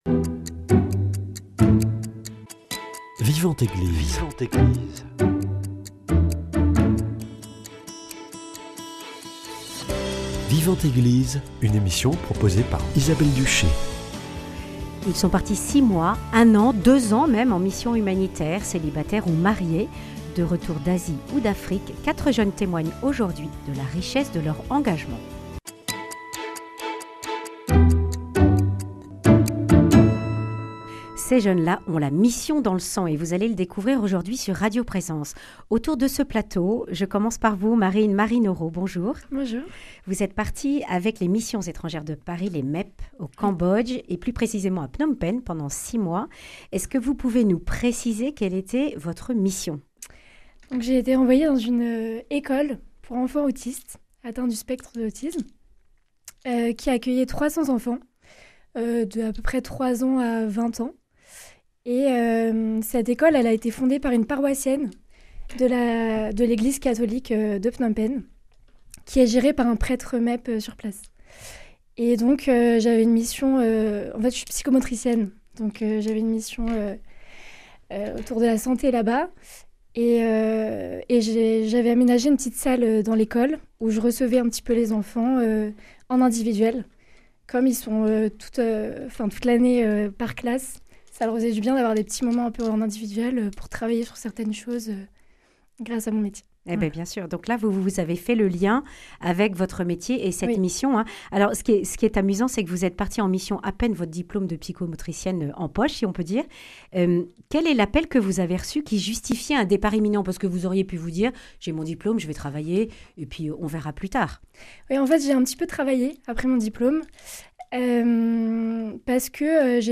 Etudiants, jeunes professionnels, célibataires ou mariés, quatre jeunes témoignent de la mission humanitaire qu’ils viennent d’achever.